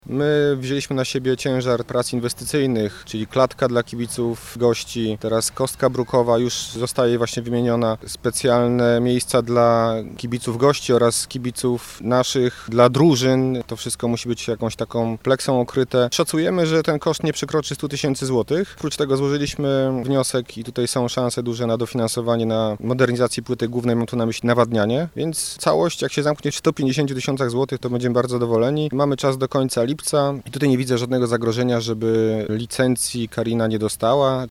’- Mamy jasny podział obowiązków między klubem, ośrodkiem sportu i samorządem – zapewnia Bartłomiej Bartczak, burmistrz Gubina.